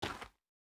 Shoe Step Gravel Hard E.wav